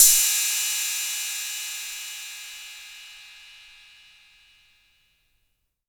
808CY_5_Tape.wav